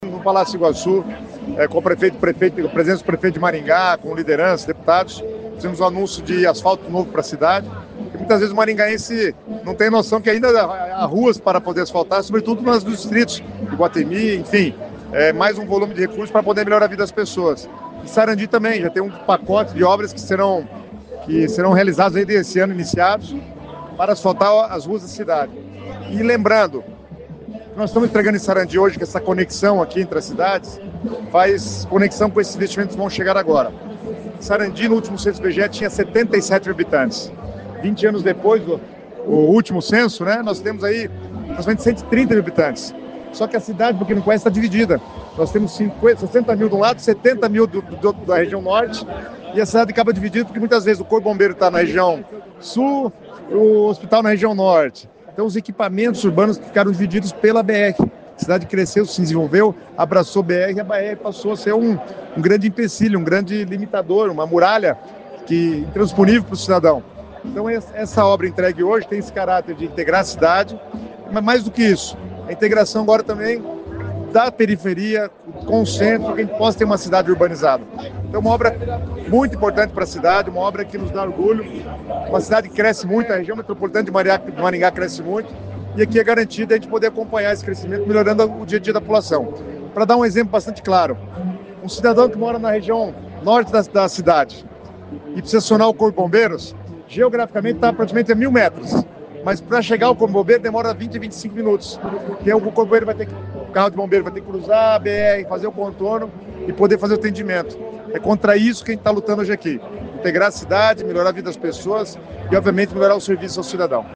O secretário das Cidades Guto Silva estava presente na inauguração e falou sobre o programa Asfalto Novo que está beneficiando cidades maiores como Maringá.